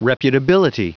Prononciation du mot reputability en anglais (fichier audio)
Prononciation du mot : reputability